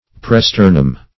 Search Result for " presternum" : The Collaborative International Dictionary of English v.0.48: Presternum \Pre*ster"num\, n. [NL.]